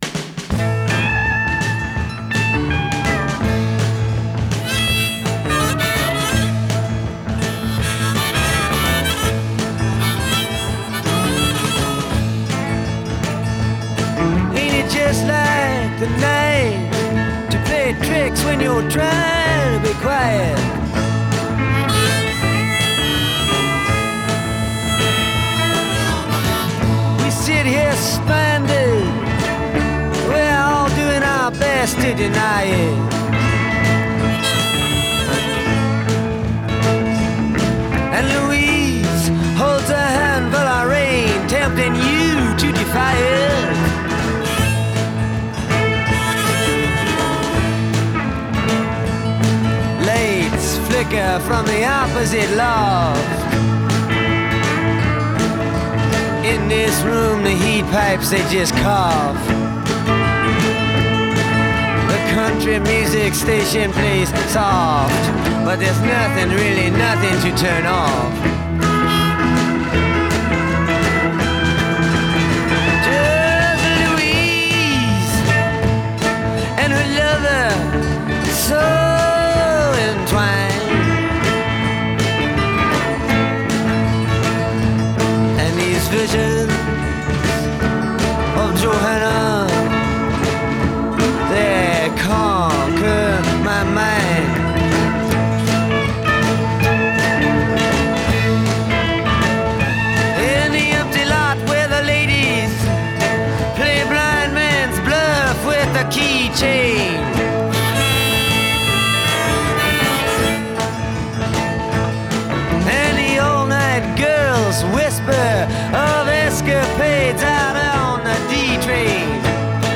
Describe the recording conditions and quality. studio outtakes